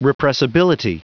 Prononciation du mot repressibility en anglais (fichier audio)
Prononciation du mot : repressibility